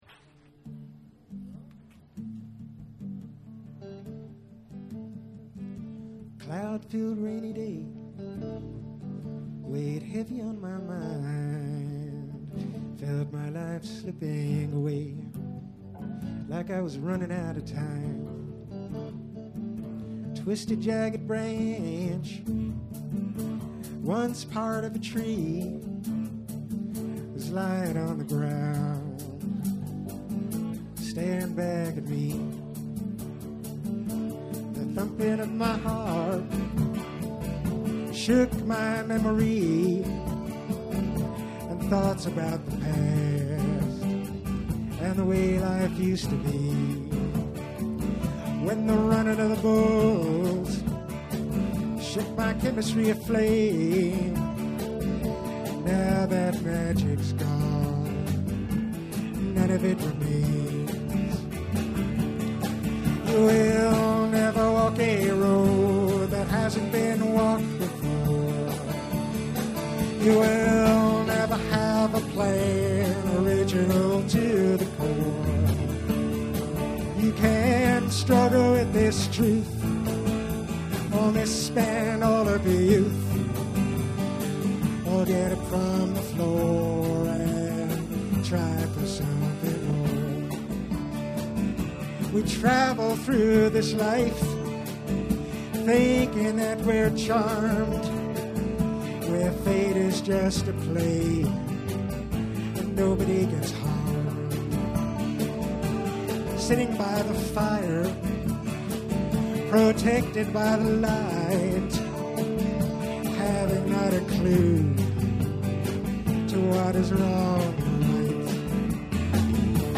Vintage Live & Rehearsal Recordings